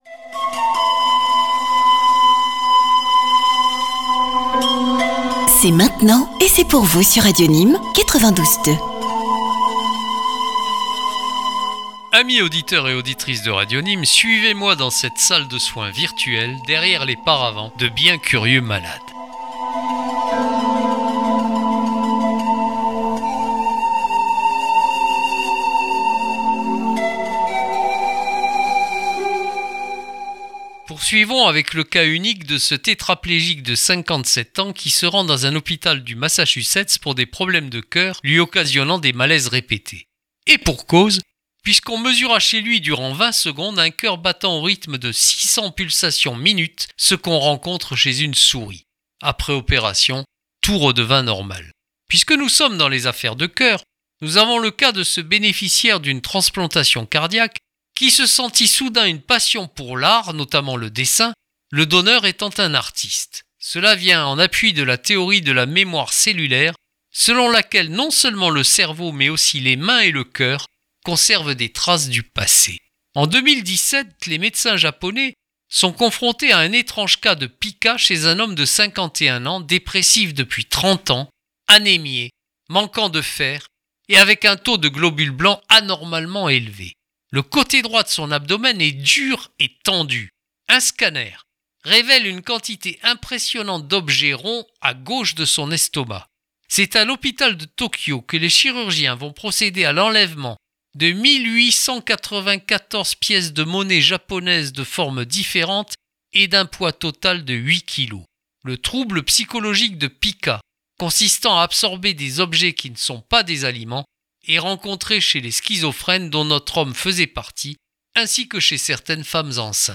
Chaque semaine, l'oncle Jacques ouvre son recueil d'histoires, toutes plus extraordinaires les unes que les autres.